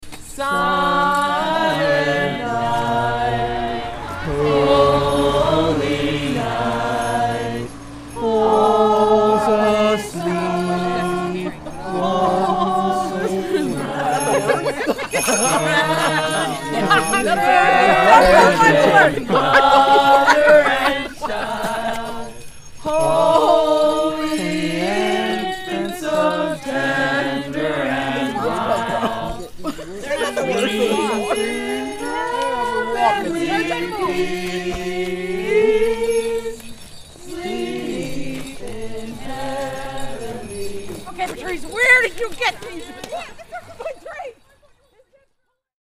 Athens carolers: Silent Night (Audio)